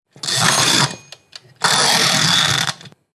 Mando del horno girando
Sonidos: Acciones humanas Sonidos: Hogar